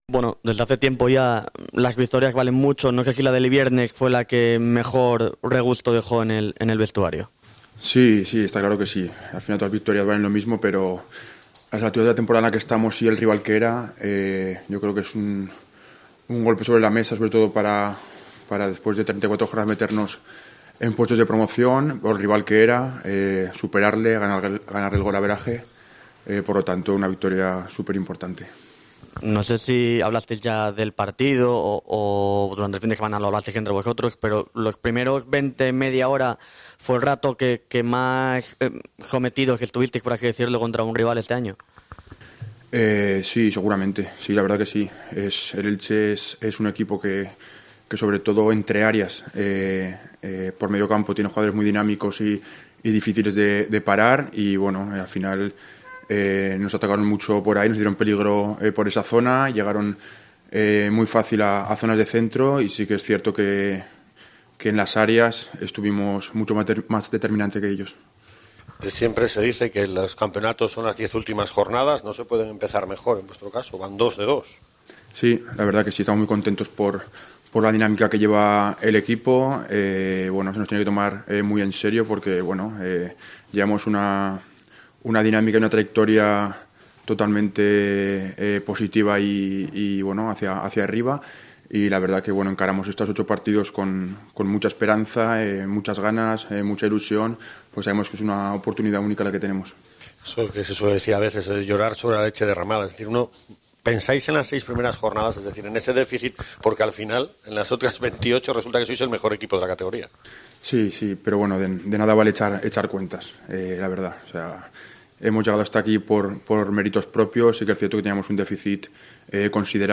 Antes de la sesión, Dani Calvo ha atendido a los medios de comunicación.